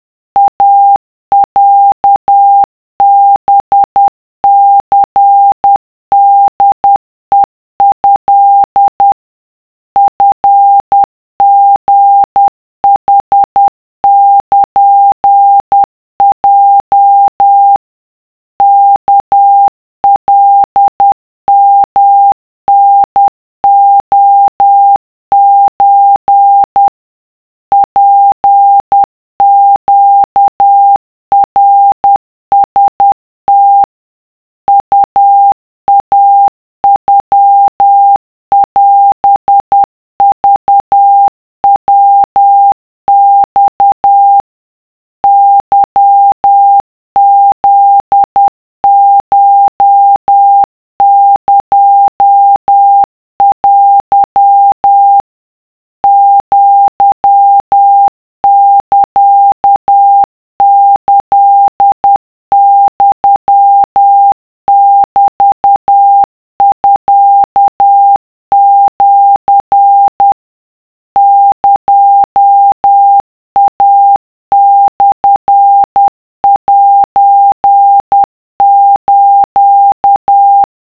【cw】2【wav】 / 〓古文で和文系〓
とりあえず50CPM (=10WPM)で作ってみた
いろはうた(80sec/50CPM)